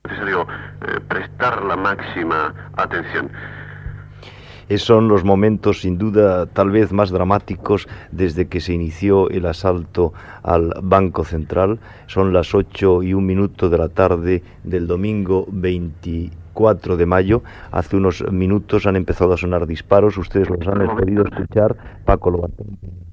Informatiu
Presentador/a